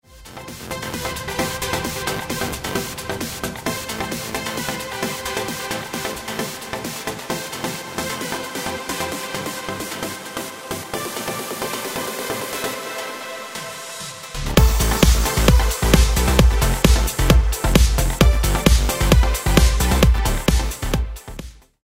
5. Melodic Tension & Resolution
One way to create melodic tension is through the purposeful avoiding of the tonic note in the melody, particularly in the verse.
Tension-and-Release-Melodic-Macro.mp3